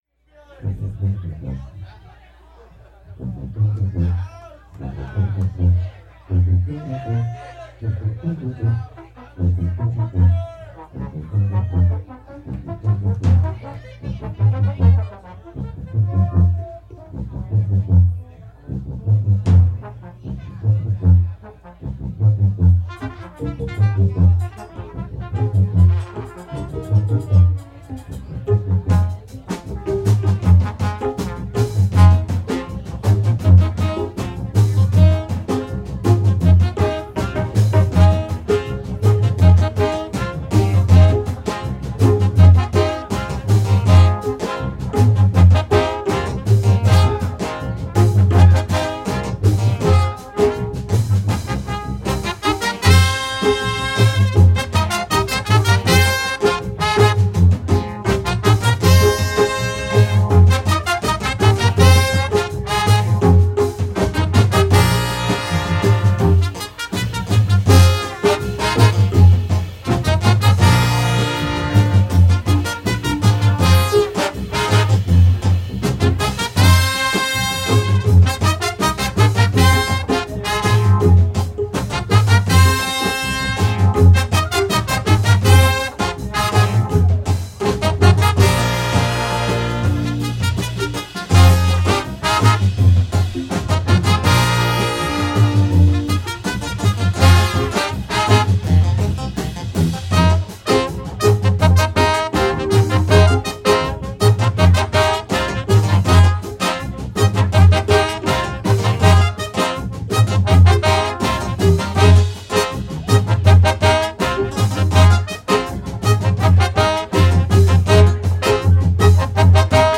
Funk
Live-Music